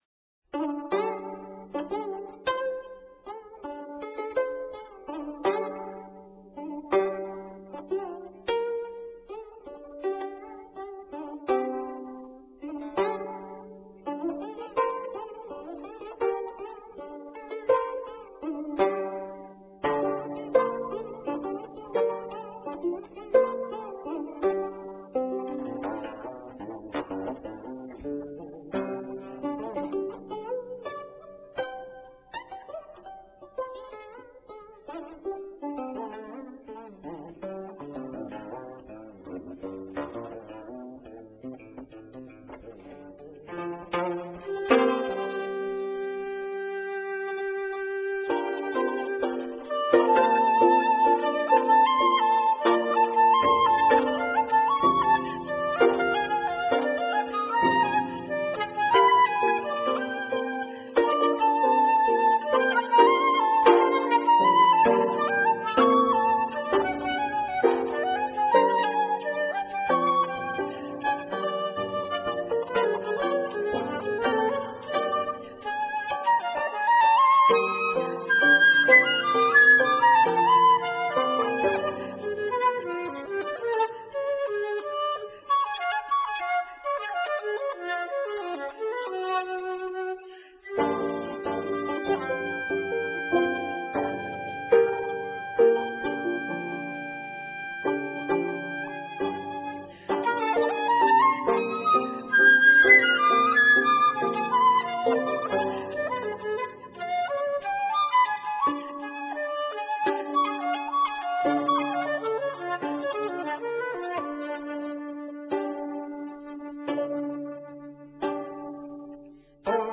Flute와 18현금을 위한